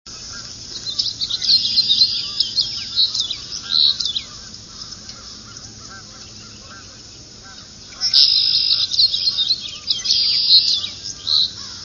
finch_house_700.wav